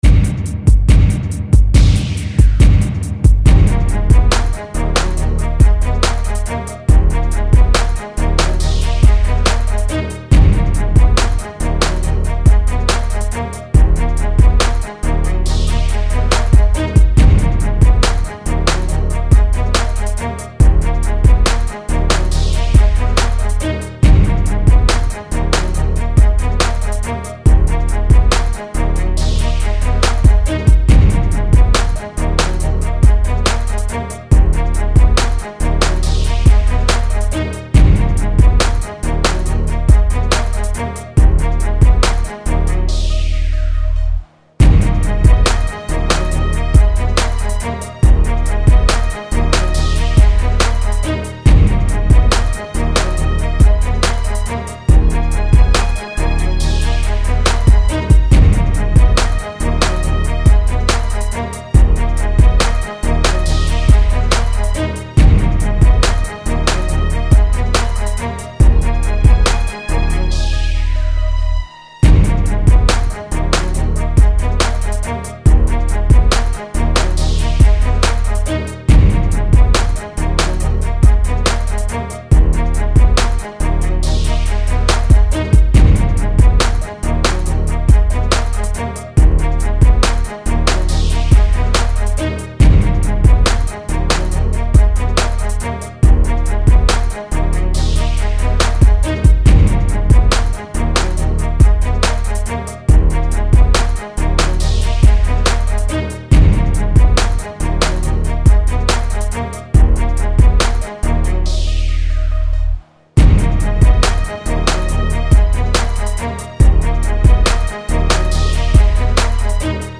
Скачать Минус
Стиль: Hip-Hop